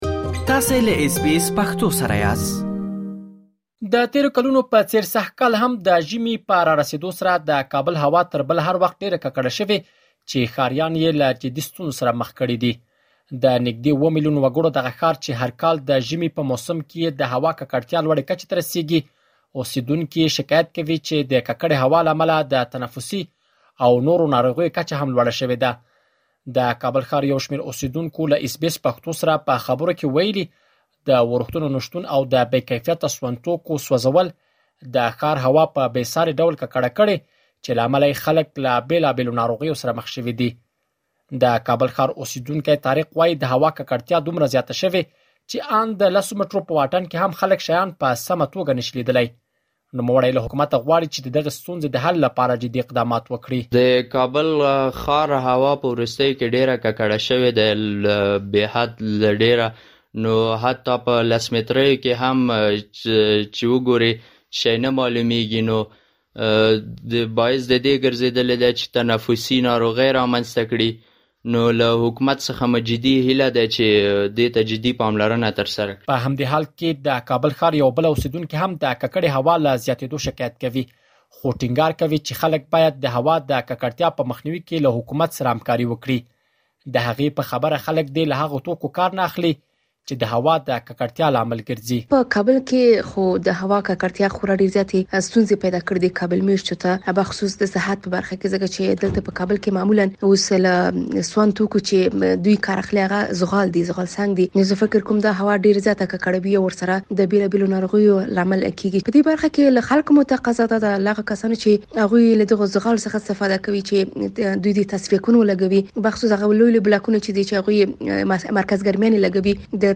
د کابل ښار یو شمېر اوسیدونکو له اس بي اس پښتو سره په خبرو کې ویلي، د ورښتونو نشتون او د بې کیفیته سون توکو سوځولو د ښار هوا په بې ساري ډول ککړه کړې چې له امله يې خلک له بېلابېلو ناروغیو سره مخ شوي دي. مهرباني وکړئ په دې اړه لا ډېر معلومات په رپوټ کې واورئ.